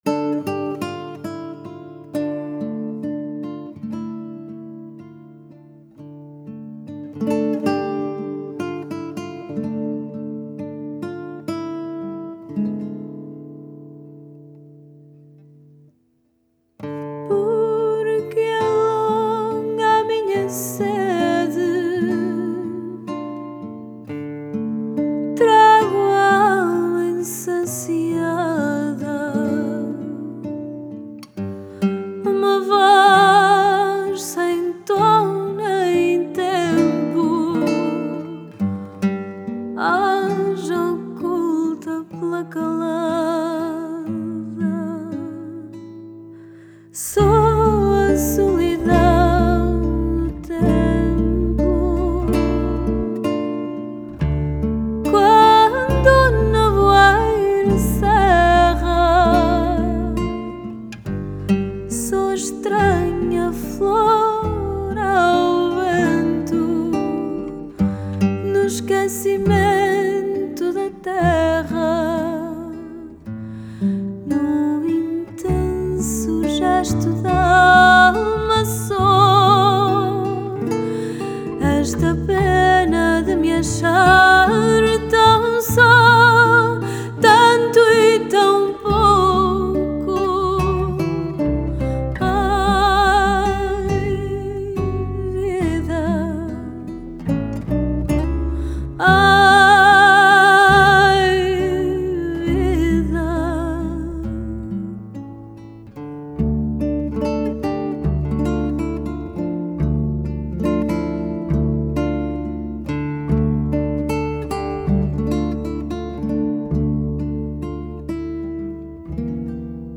Style: Fado